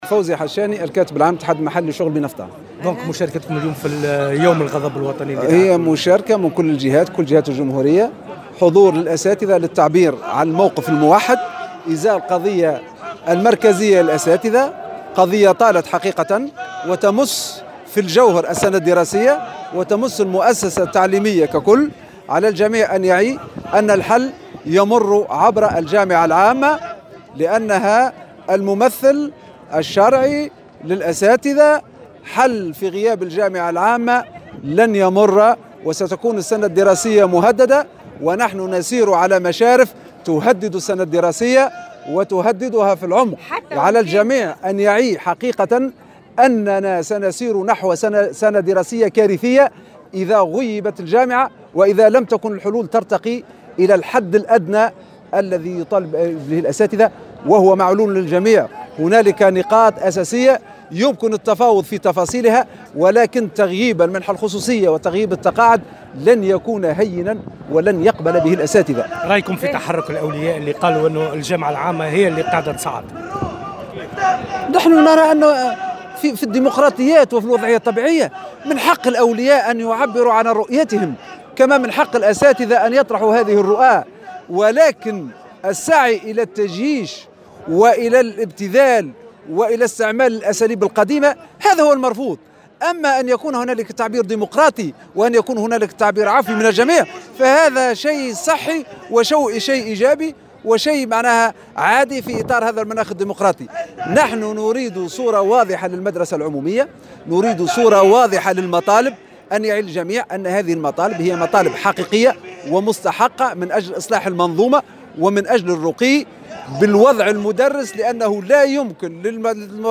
وكان لمراسل "الجوهرة اف أم" لقاءات مع بعض المحتجين